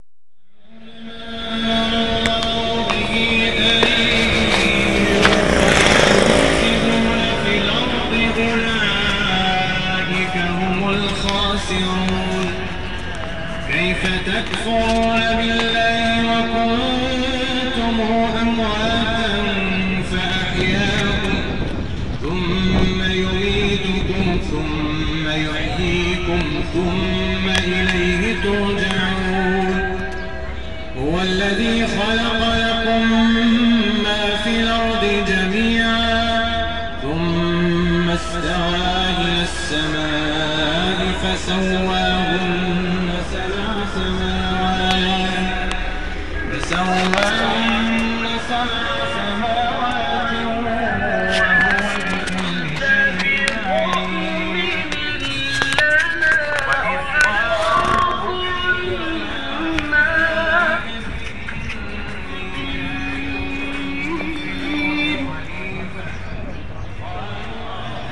I recorded two audio clips from Rabat.
the first one you can hear the music Moroccans typically listen to.
rabat_song.ogg